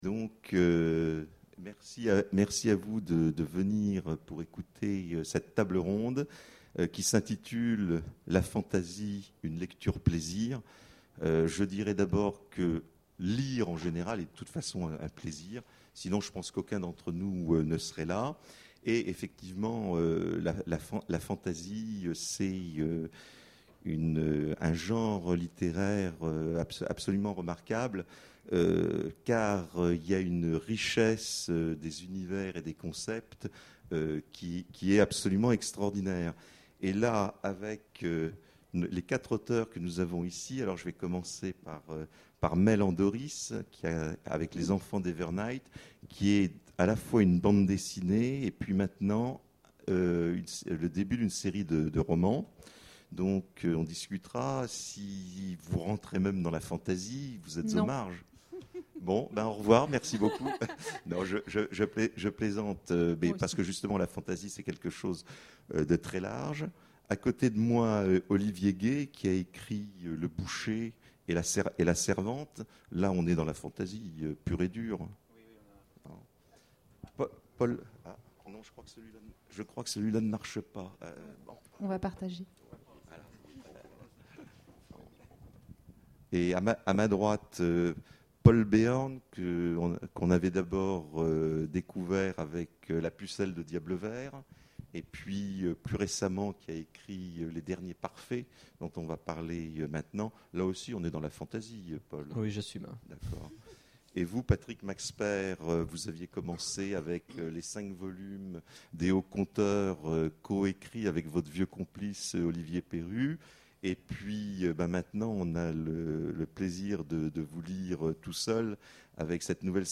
Imaginales 2014 : Conférence La fantasy... Une lecture plaisir!